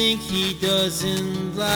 Removing scratch (static?)
I guess it must be some magnetic disturbance due to poor storage of a DAT or ADAT tape from which this was transfered years ago.
The visible spikes are the worst damaged parts, but there is audible damage throughout most of the audio, which is too small to see, but still sounds crackly / bad.